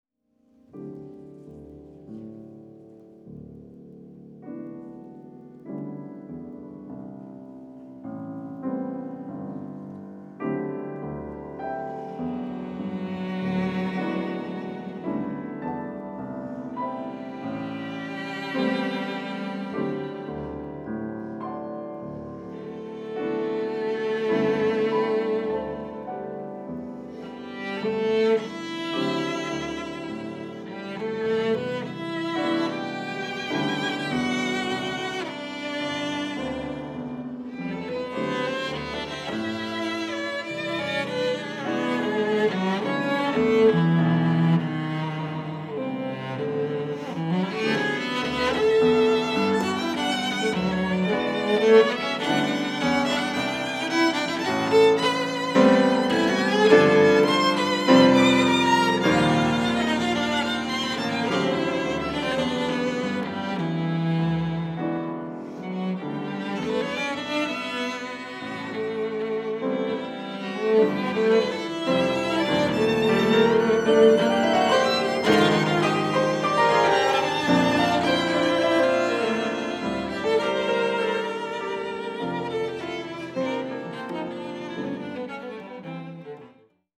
Instrumentation: violin, cello, piano